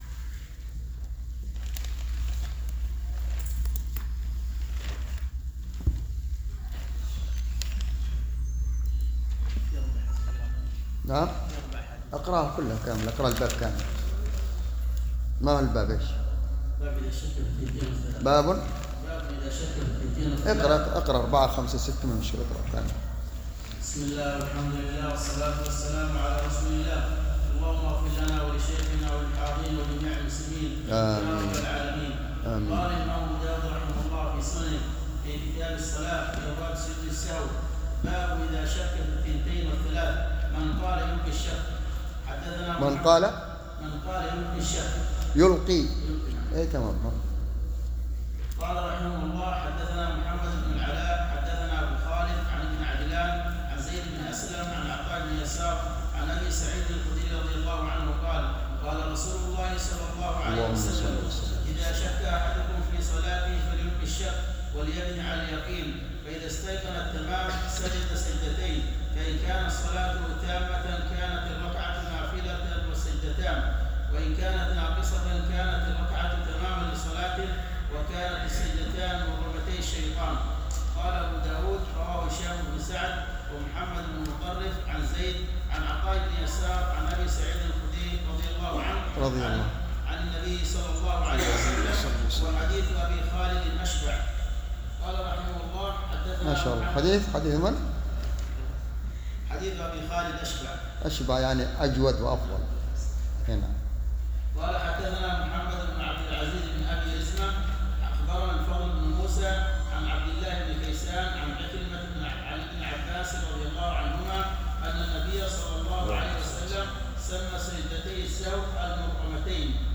شرح كتاب الصلاة - سنن أبي داود | ٢٠ رجب ١٤٤٤ هـ _ بجامع الدرسي صبيا